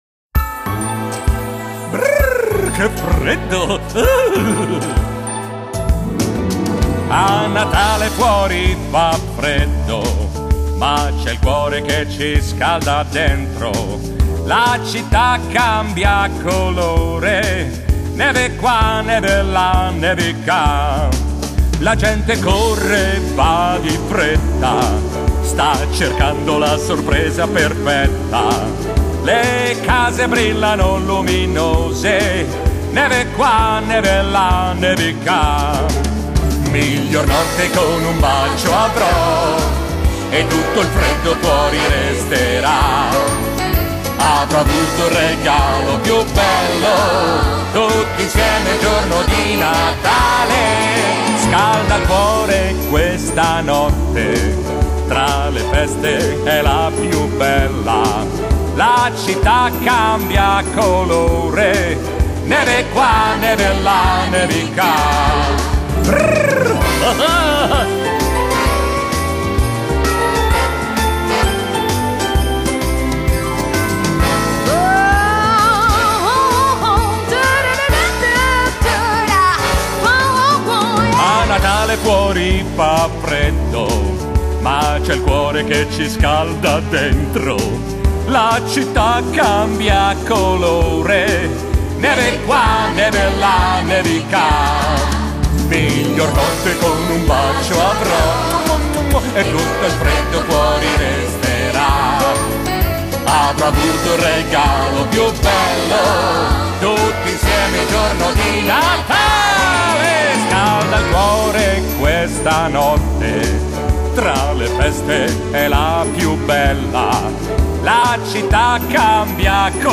coro dei genitori- Dicembre 2018